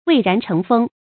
注音：ㄨㄟˋ ㄖㄢˊ ㄔㄥˊ ㄈㄥ
蔚然成風的讀法